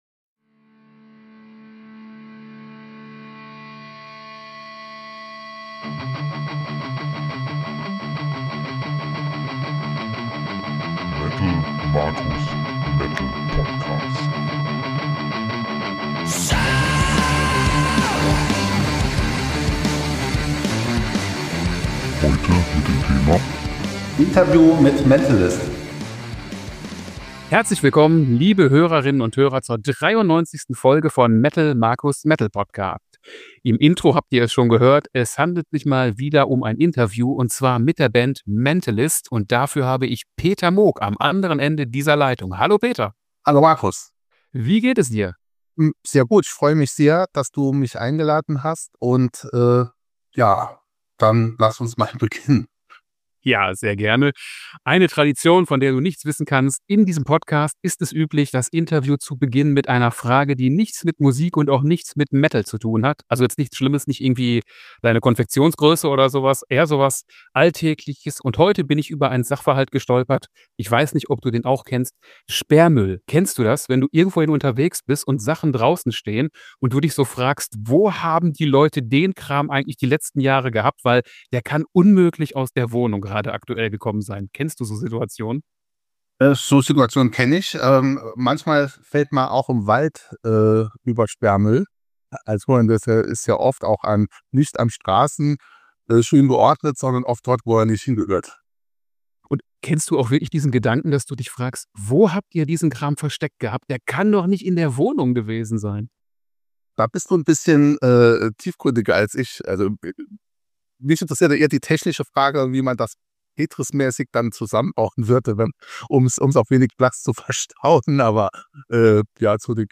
Folge 93 - Interview mit Mentalist